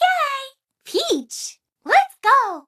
Peach's voice clip when selected from the file screen in Super Mario 3D World
SM3DW_Peach_Yeah.oga.mp3